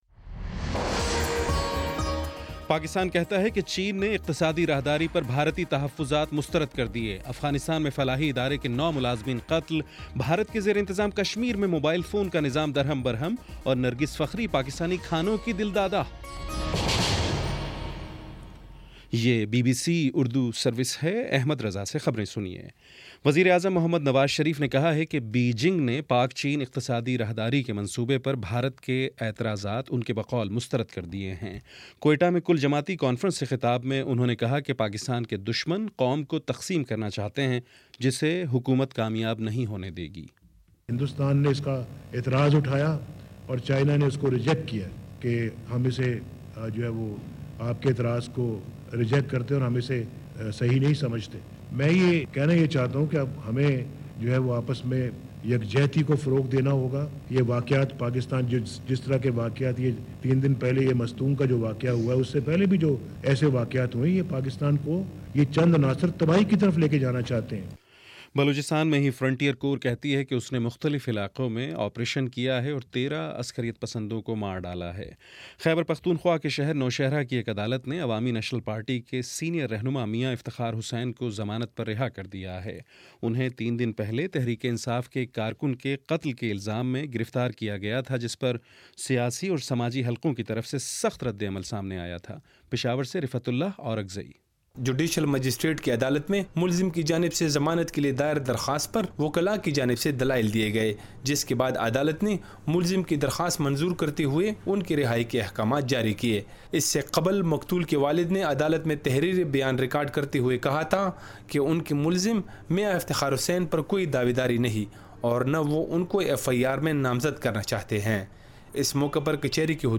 جون 2: شام چھ بجے کا نیوز بُلیٹن